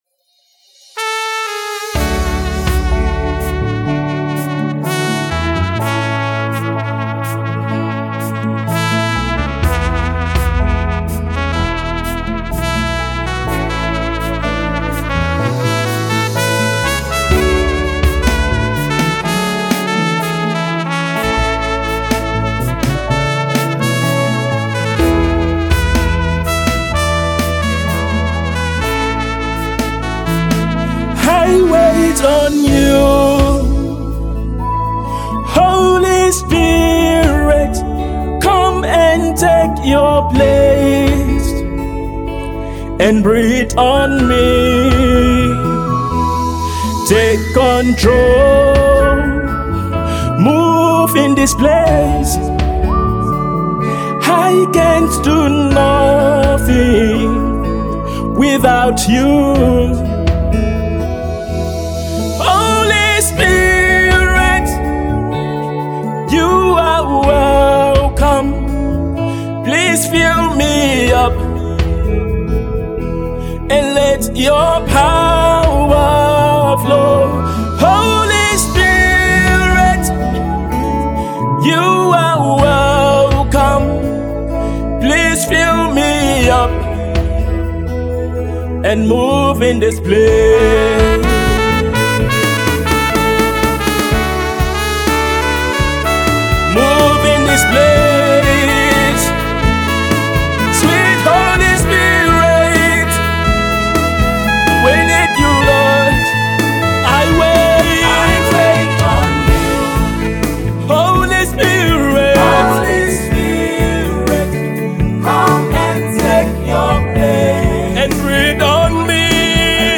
Nigerian Gospel Minister and Songwriter